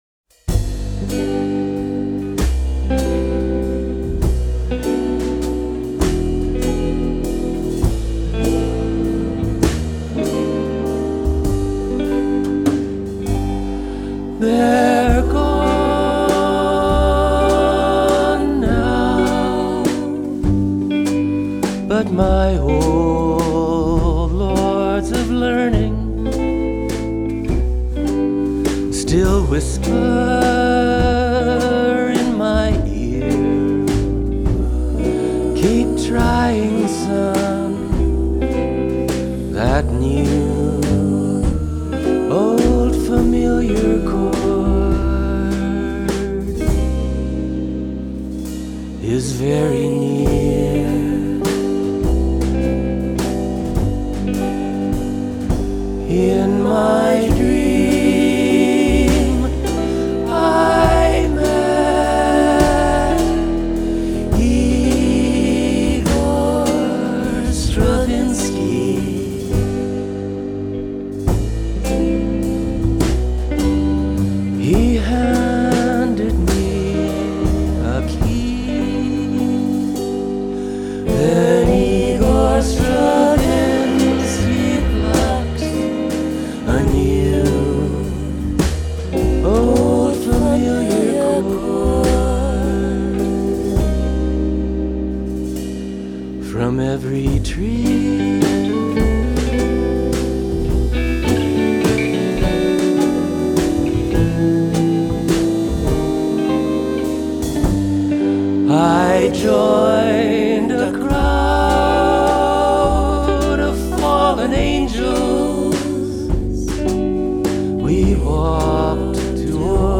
piano/guitar/vocals
vocals
guitar
bass
drums